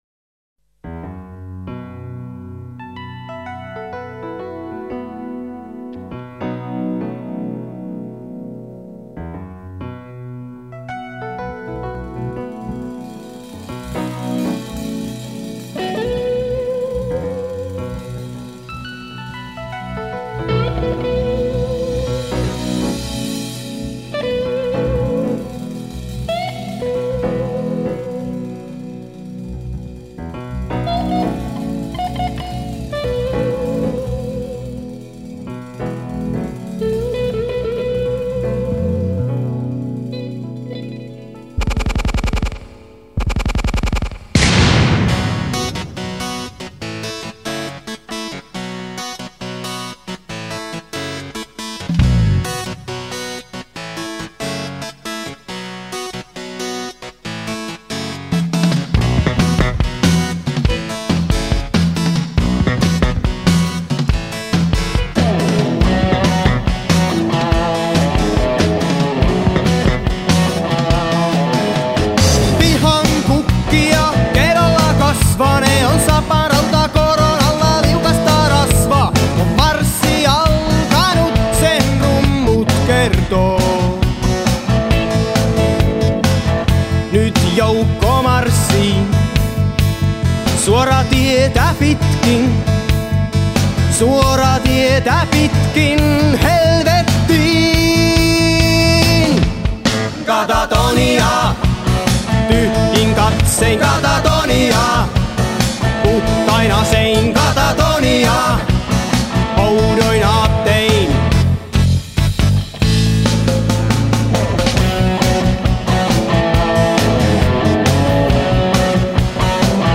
laulu, taustalaulu, basso, kitara
koskettimet, taustalaulu
kitarat
rummut, taustalaulu